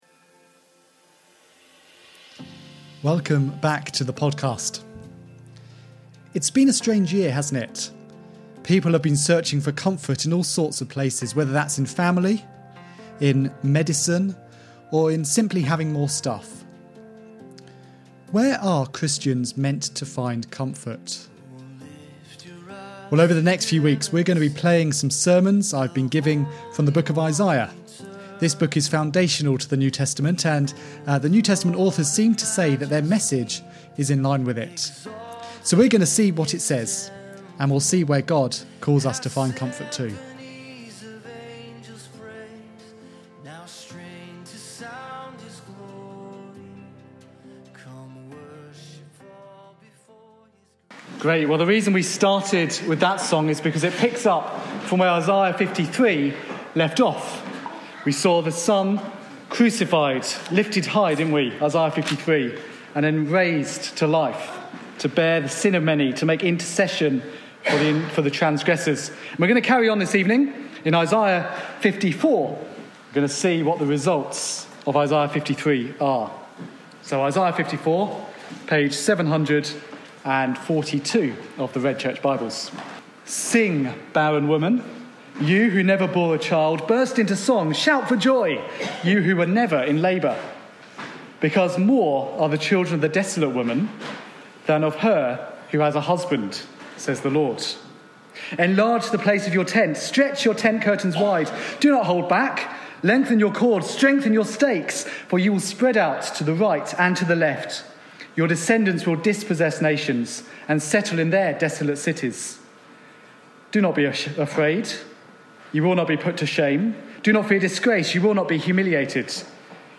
This was preached at Christ Church Hemel on 24th April 2022.